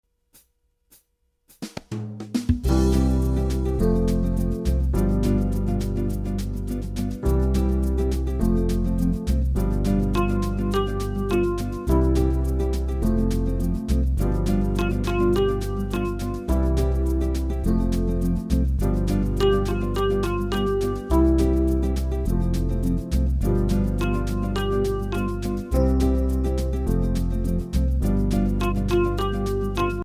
Voicing: Electric Piano